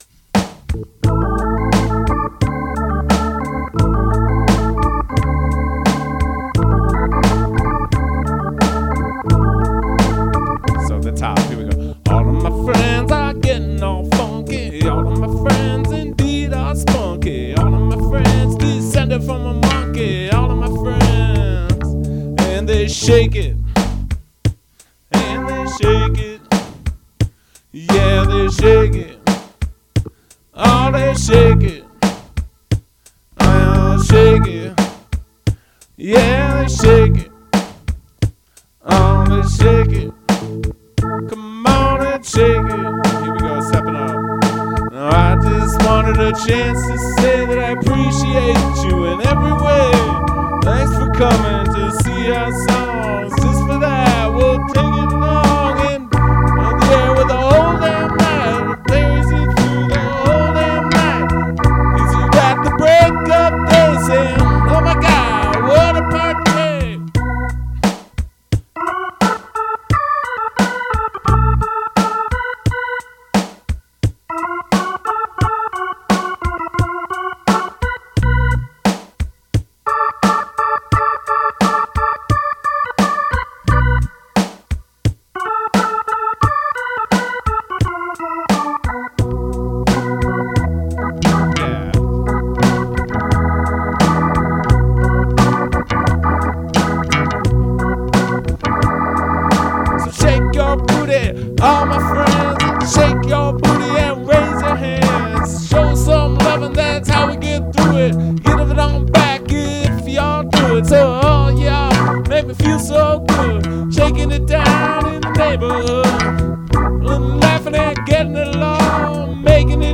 Rehearsal